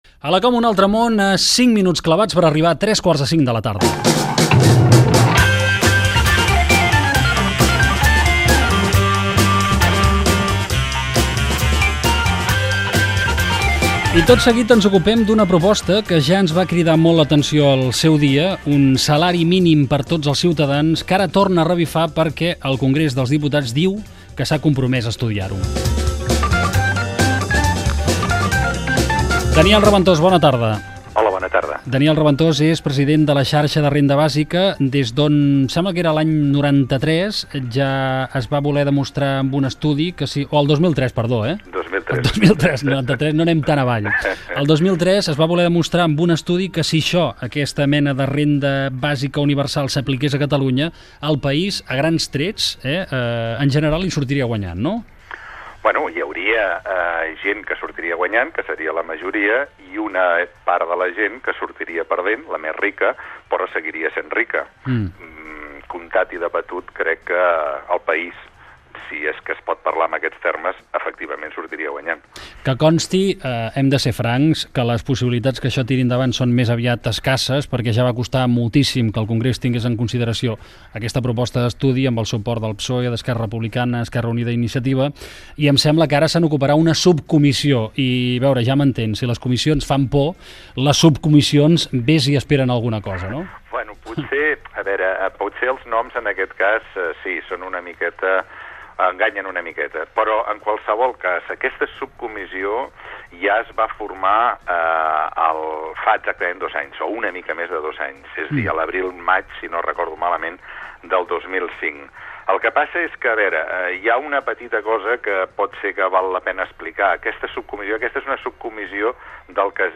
Hora, identificació del programa i fragment d'una entrevista
Entreteniment
FM